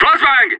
CSGO Flassbang!
csgo-flassbang.mp3